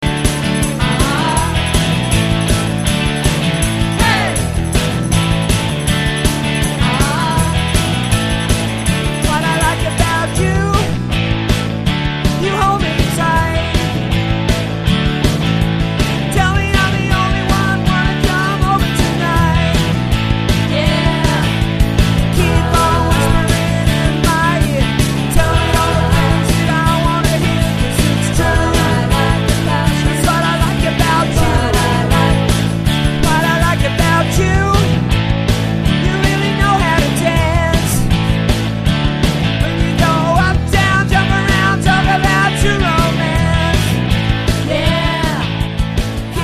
Guitar Vocals - Female Vocals - Keyboard - Bass - Drums - 2nd Female Vocal
delivering an energetic, fresh and interactive performance.